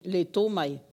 Collectif patois et dariolage